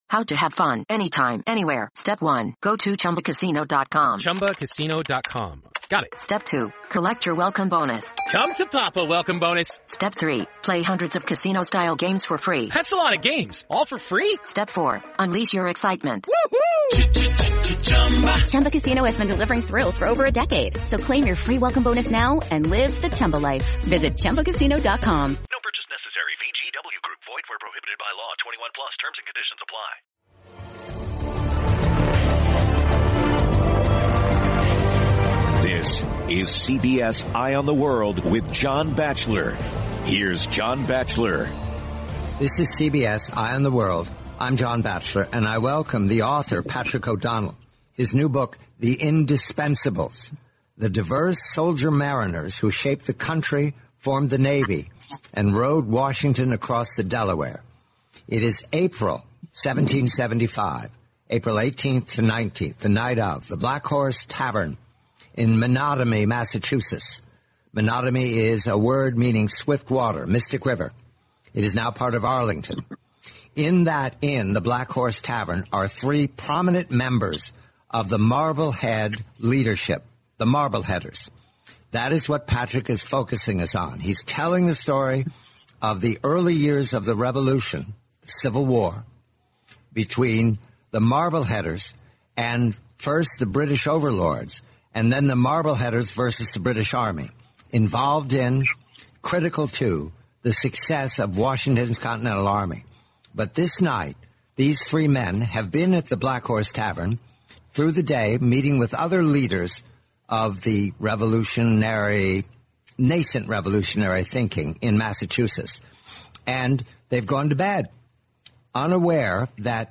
The complete eighty-minute interview, June 6, 2021.